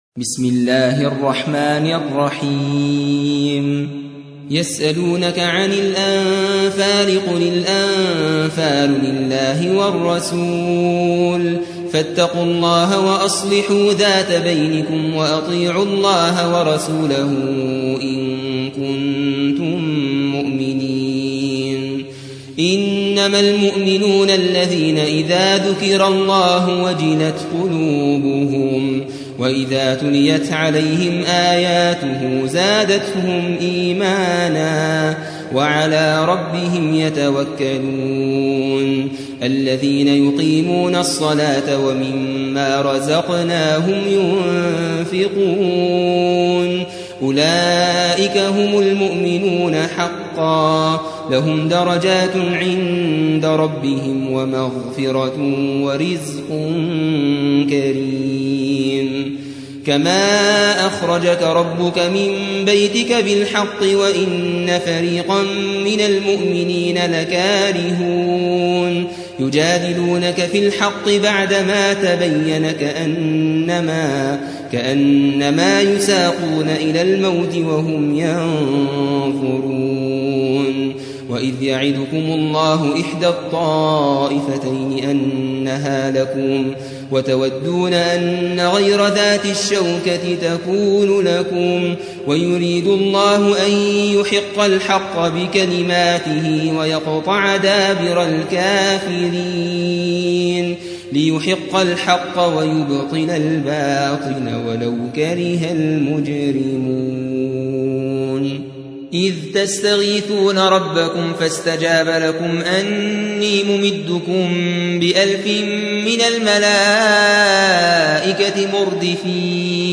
8. سورة الأنفال / القارئ